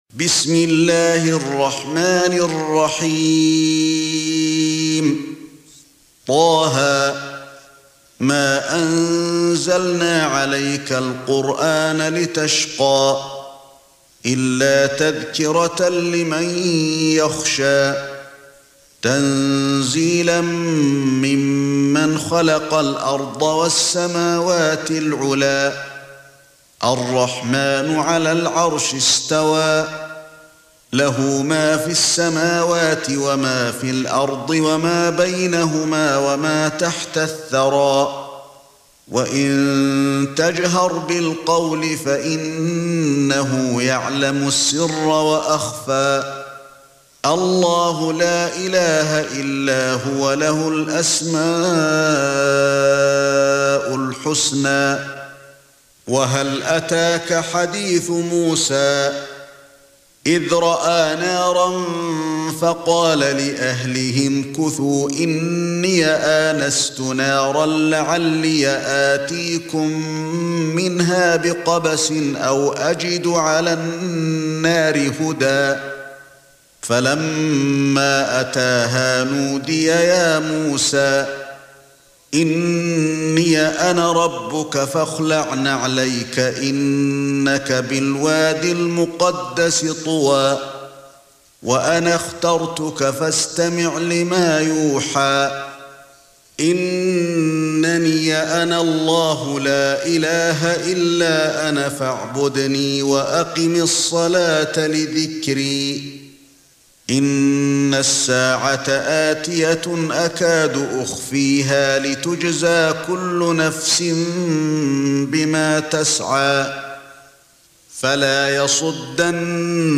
سورة طه ( برواية قالون ) > مصحف الشيخ علي الحذيفي ( رواية قالون ) > المصحف - تلاوات الحرمين